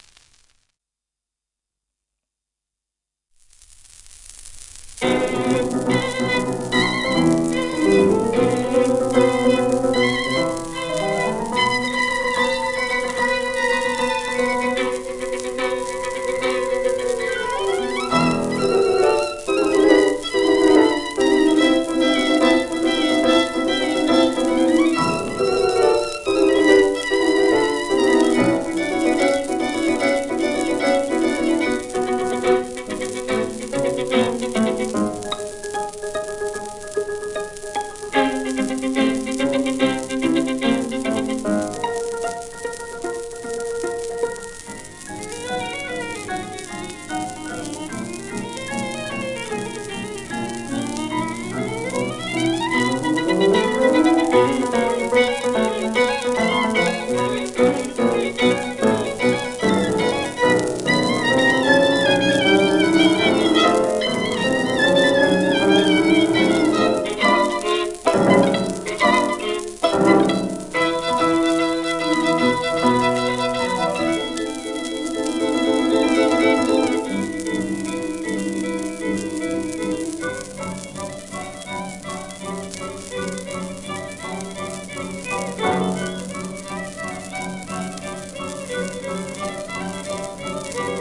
1945年録音
シェルマン アートワークスのSPレコード